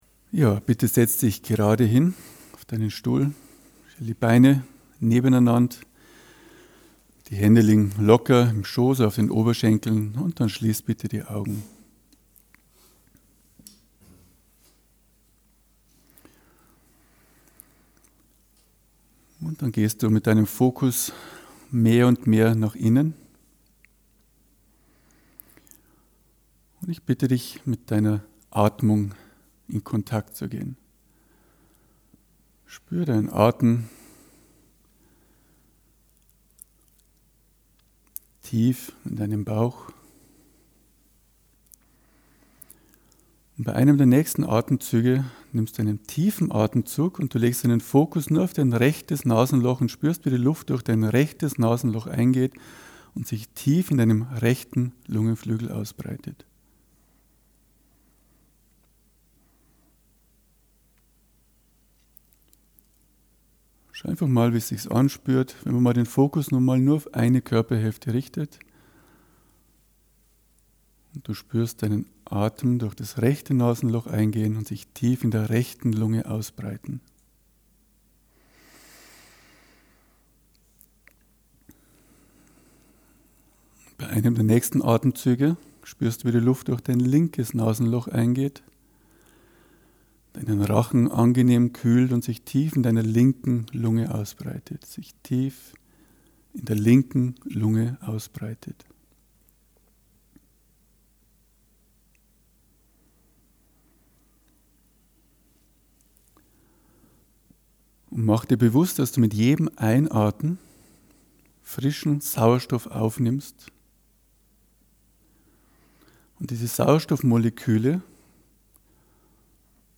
Reise in Deinen Körper Machen Sie eine Reise in Ihren Körper und staunen Sie, wohin Sie die Weisheit Ihres eigenen Körpers führt. Diese geführte Meditation
wurde aufgenommen anlässlich des jährlichen Therapeutentreffens der Hypnosetherapeuten des Instituts für Klinische Hypnose im Dezember 2008.